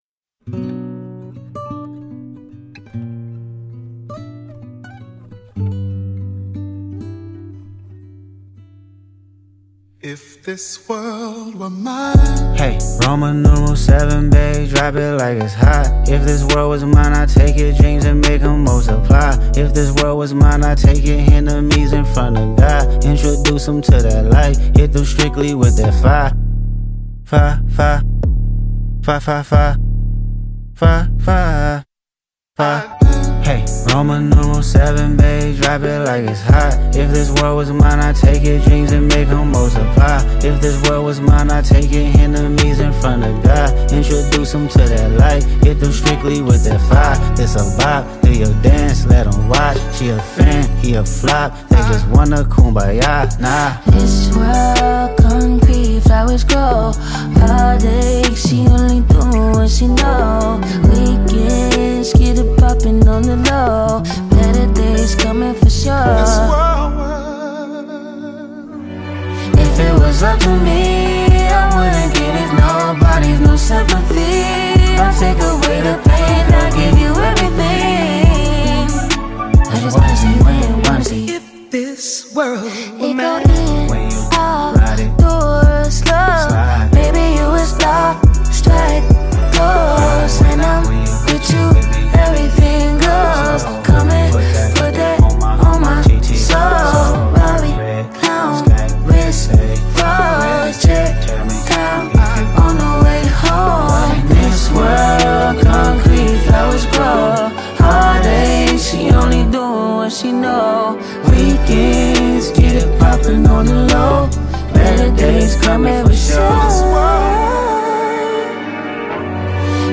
captivating instrumentals
With nice vocals and high instrumental equipments